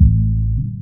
FILT_DEPTH_BASS.wav